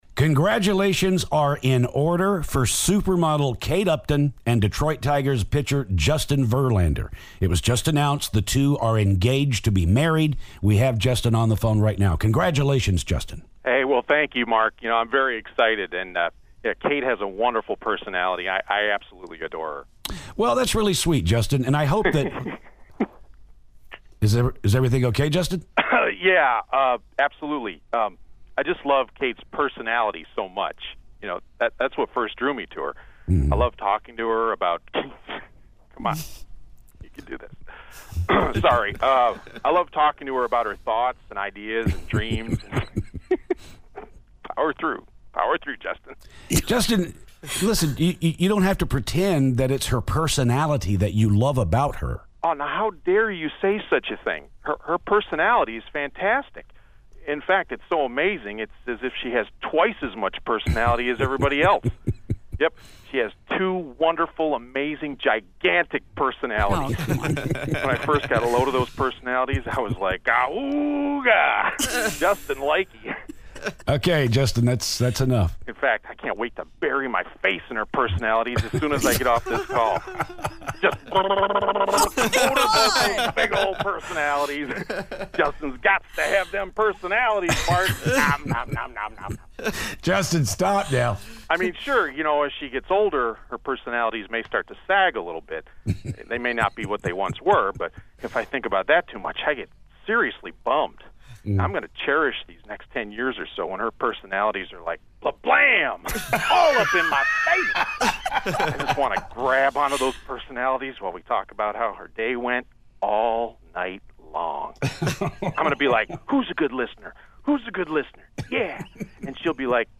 Justin Verlander Phoner